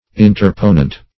Search Result for " interponent" : The Collaborative International Dictionary of English v.0.48: Interponent \In`ter*po"nent\, n. One who, or that which, interposes; an interloper, an opponent.